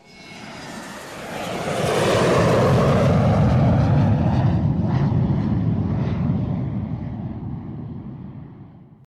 Jet.wav